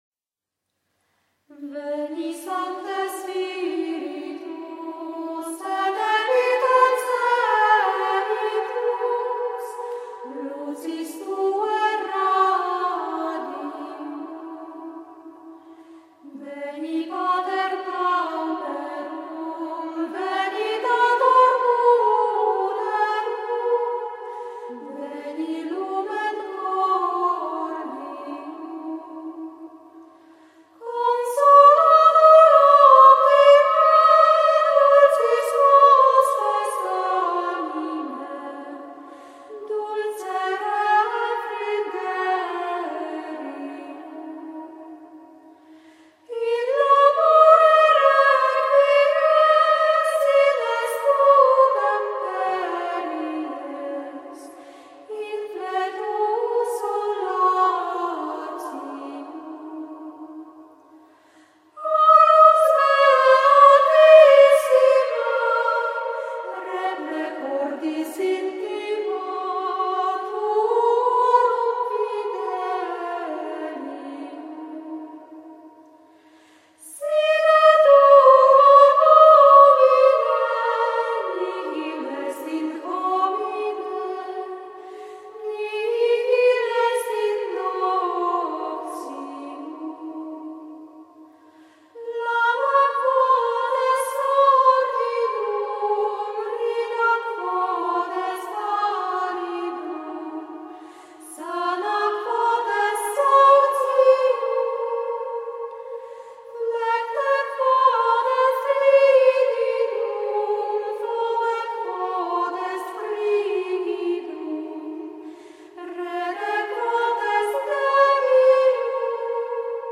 • veni sancte spiritus grégorien séquence
La mélodie, quant à elle, regroupant les strophes de deux en deux, se fonde sur celle de l’alléluia qui a précédé (c’est précisément le sens du mot séquence) et se déploie avec souplesse sur l’échelle mélodique du 1er mode, rompant çà et là le syllabisme pur de sa ligne au moyen de quelques neumes chaleureux qui mettent surtout en valeur les accents des mots.
Un courant d’intensité anime différemment chaque paire de strophes, donnant à l’ensemble un caractère vivant qui authentifie la beauté mystique de la pièce.
Les deux premières strophes partent du Do grave, sous-tonique du mode de Ré, et progressent doucement et calmement vers le Sib du second vers.
C’est tout simple, mais c’est admirablement équilibré, et très paisible.
Les deux strophes suivantes commencent à l’aigu et en un bel élan, directement sur le La qui n’avait pas encore été écouté.